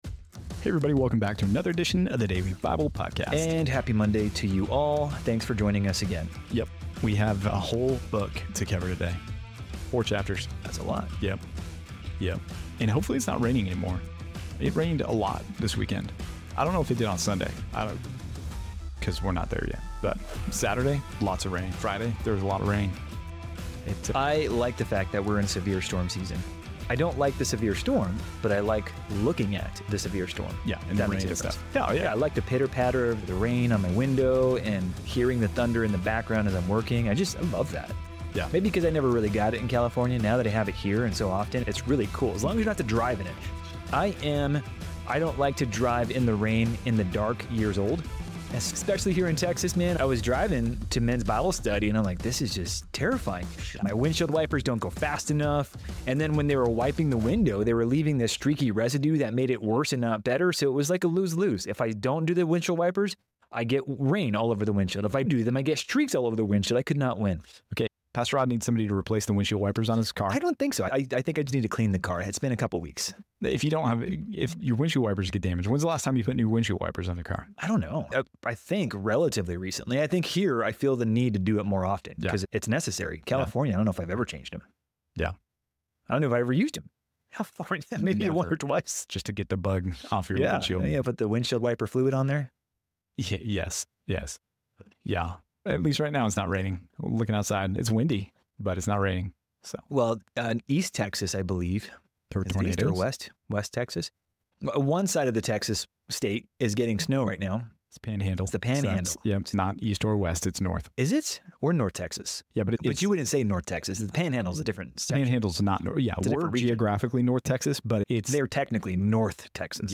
In this installment of the Daily Bible Podcast, the hosts cover the entire Book of Ruth, which includes four chapters detailing the lives of Ruth, Naomi, and Boaz during the time of the judges. The conversation touches on the themes of loyalty, faithfulness, and God's overarching plan even in dark times. The discussion highlights Ruth's conversion, the concept of Leverite marriage, and Boaz's integrity and role as a redeemer.